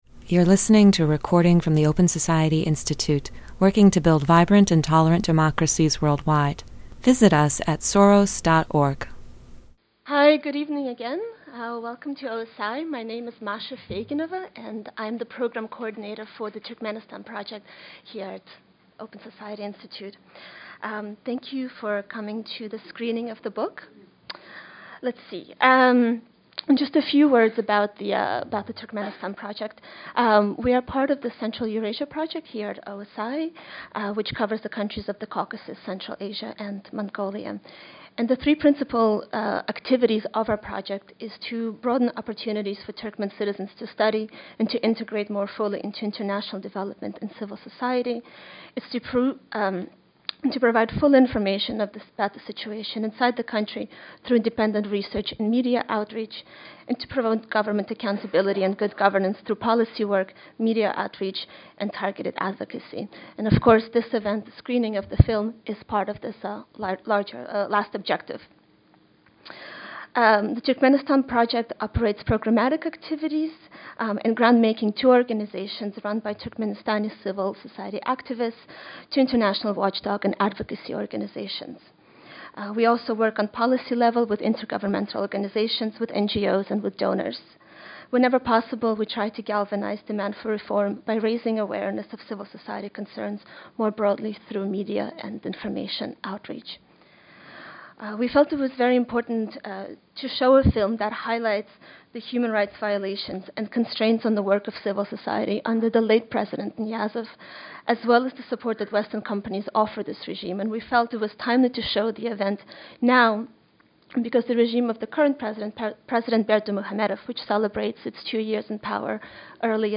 Experts discuss the secretive and repressive dictator of Turkmenistan and the Western companies who did business with him.